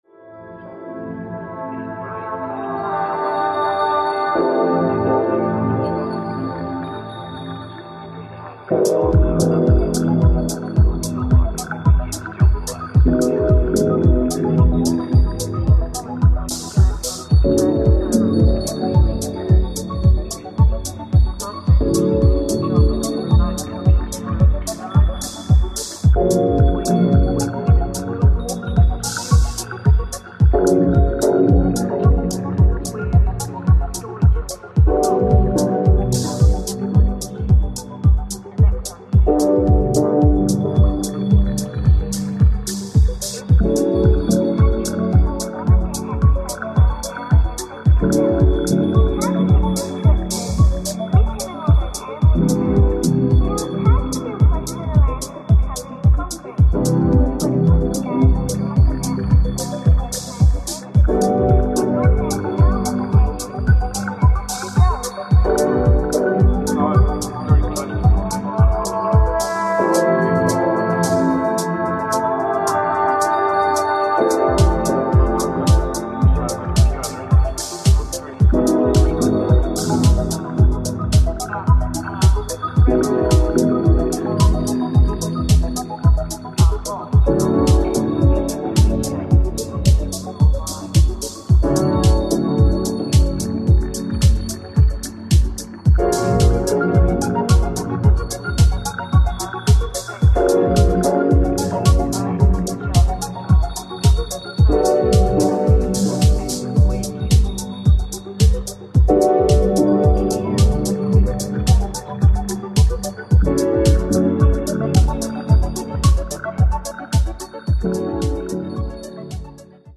Jazz
Meditative synth lines
bass guitar
Ambient